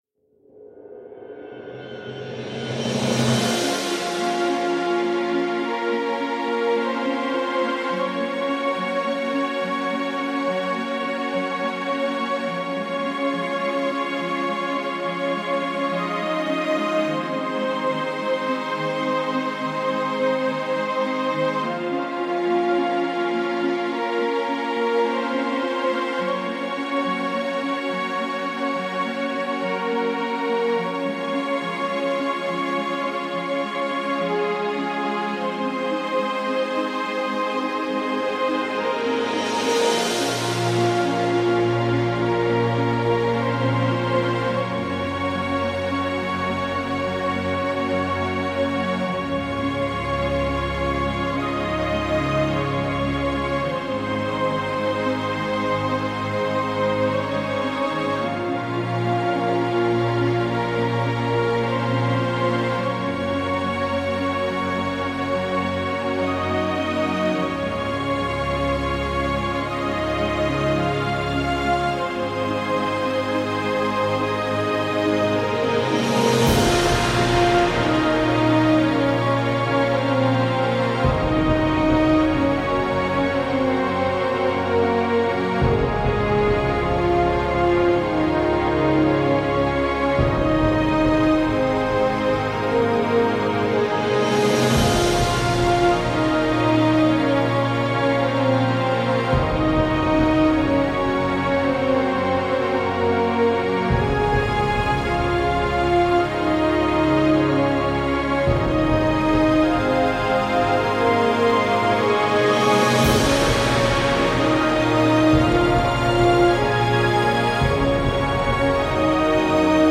ボス戦BGM フリー音楽ダウンロード
タグ: 動機付け、古典的、叙事詩